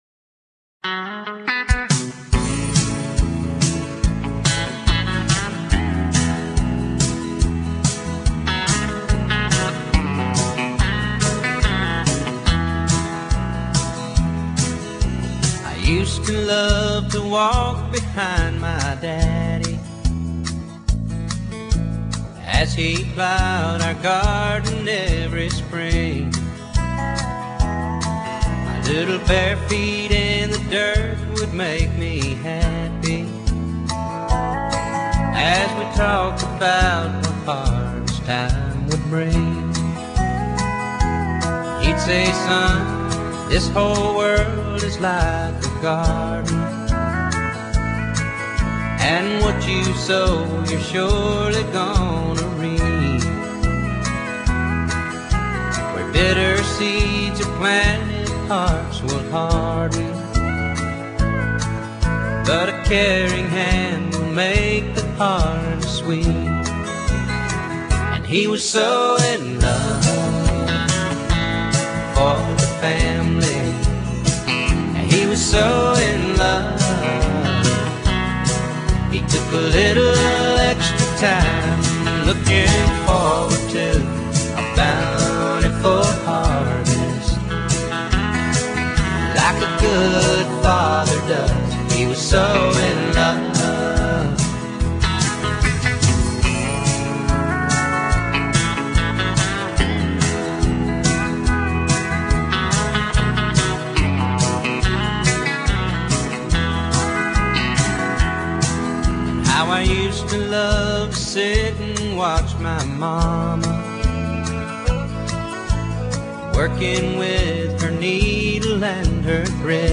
乡村音乐不像纯古典音乐離自己很遥远；也不像摇滚、重金属音乐那样嘈杂。